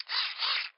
Crank sound
crank.ogg